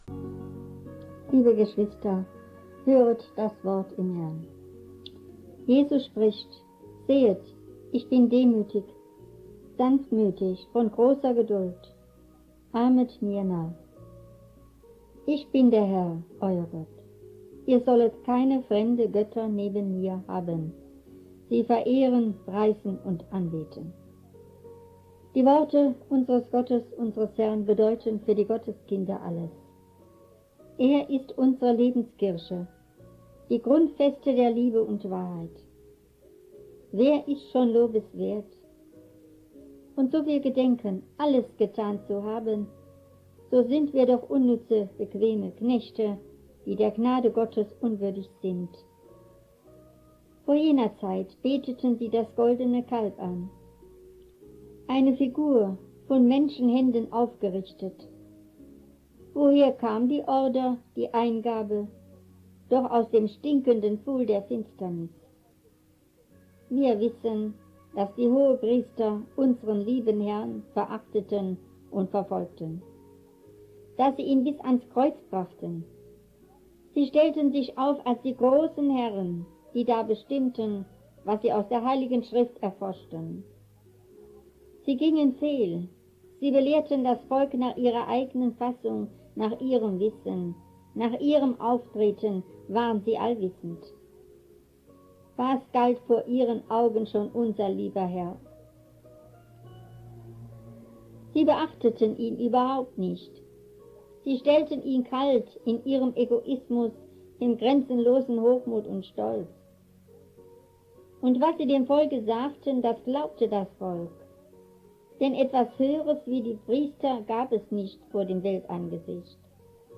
2. predigt vom sept.1988.ogg